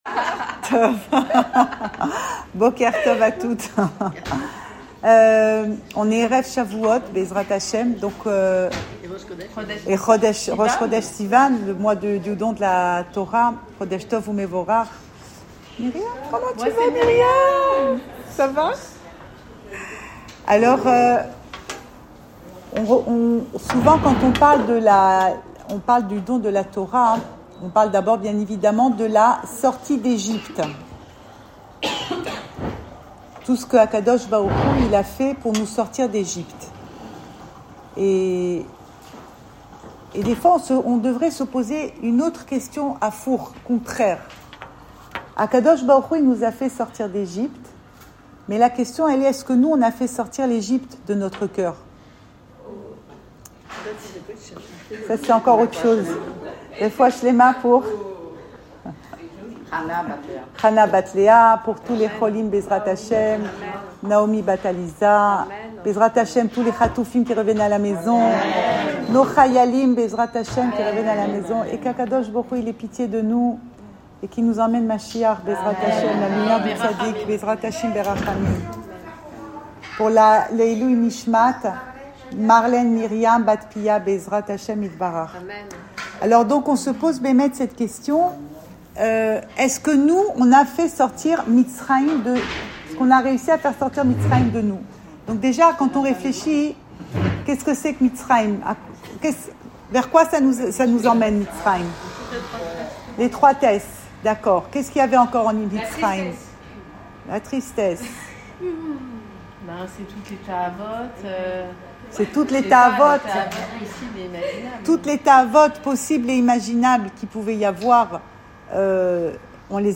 Cours audio Le coin des femmes Le fil de l'info Pensée Breslev - 28 mai 2025 28 mai 2025 La folie des grandeurs. Enregistré à Tel Aviv